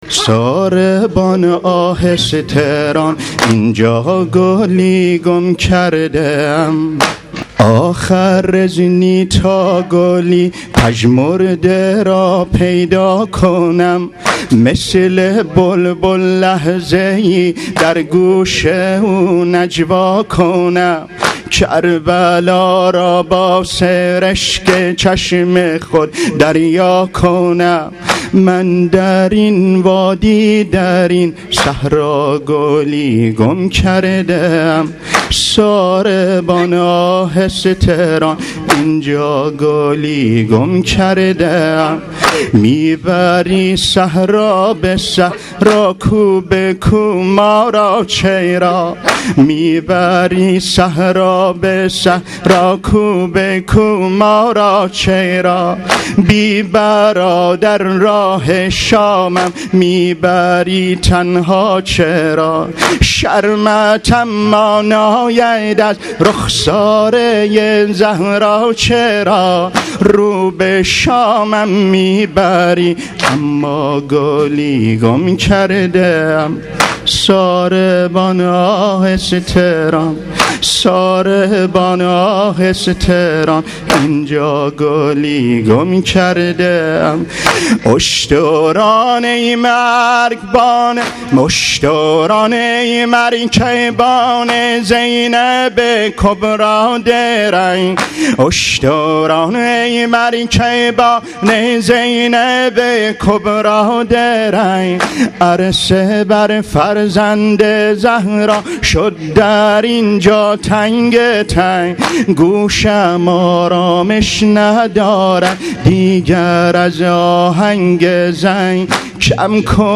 برچسب ها: نوحه بوشهری ، دانلود